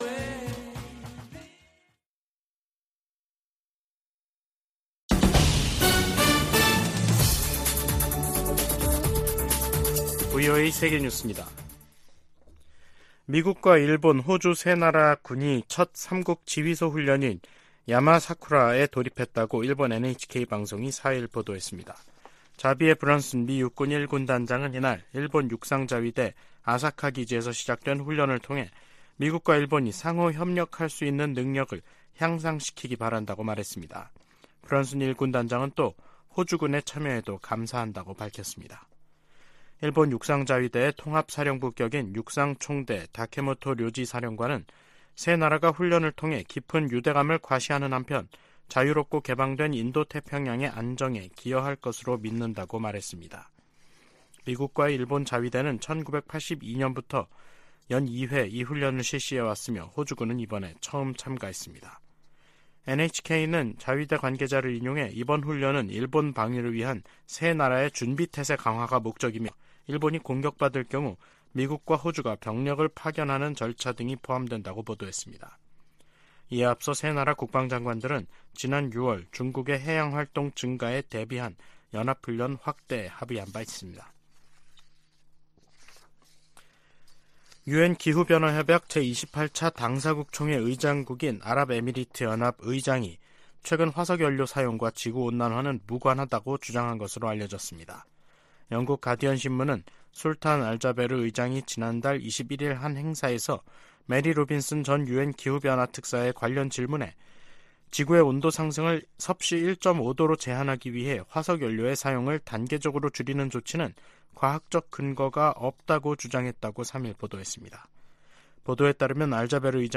VOA 한국어 간판 뉴스 프로그램 '뉴스 투데이', 2023년 12월 4일 3부 방송입니다. 북한에 이어 한국도 첫 군사정찰위성 발사에 성공하면서 남북한 간 위성 경쟁이 치열해질 전망입니다. 줄리 터너 미 국무부 북한인권특사가 1~5일 로스엔젤레스와 호놀룰루를 방문해 북한 인권 관계자 등을 만난다고 국무부가 밝혔습니다. 미국과 한국이 '제3차 민주주의∙거버넌스 협의체' 회의를 개최하고 인권과 민주주의 증진 방안을 논의했습니다.